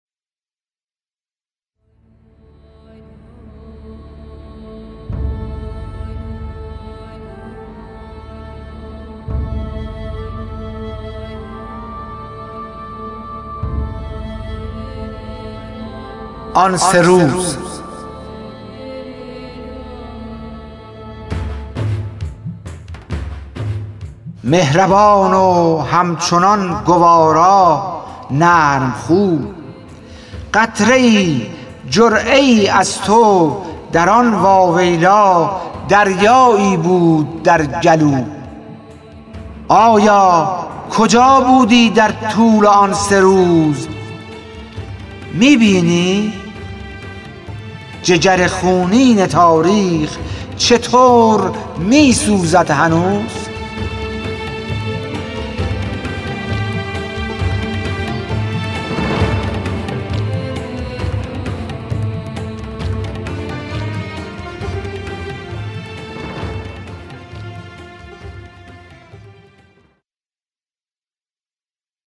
خوانش شعر سپید عاشورایی / ۲